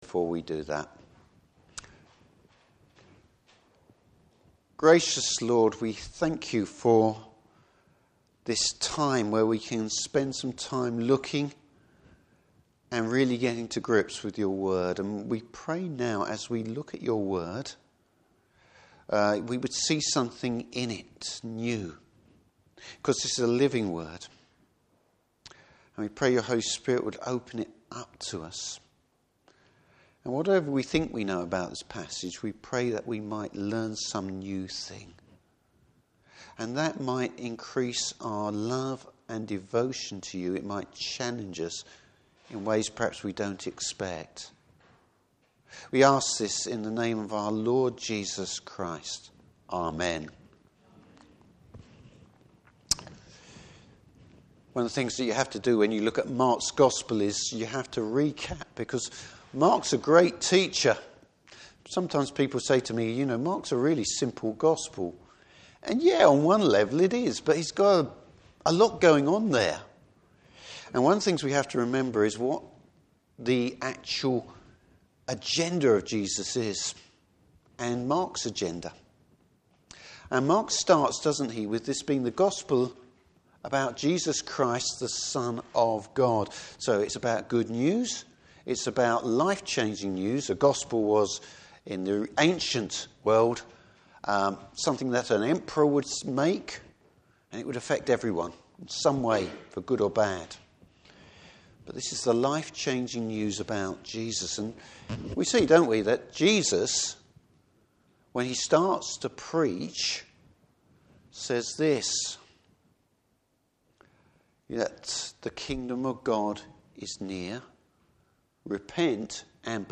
Service Type: Morning Service Jesus demonstrates the ultimate authority.